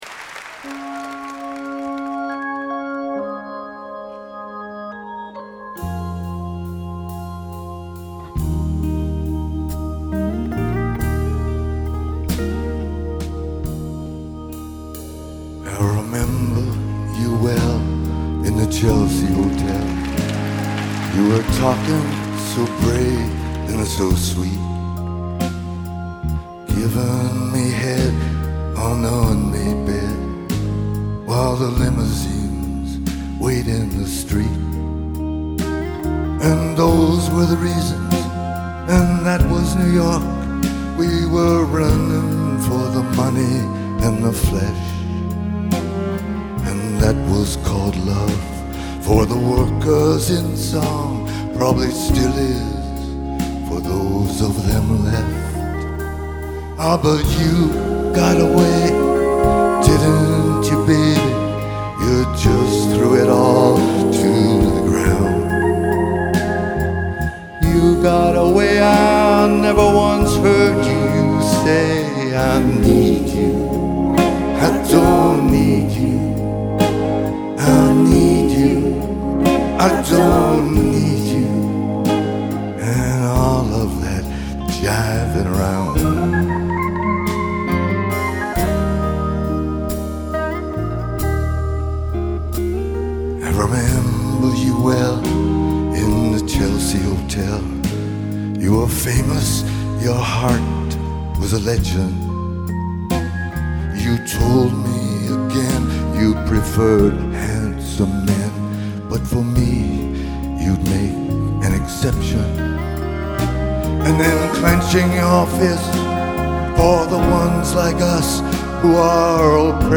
Live Nov 17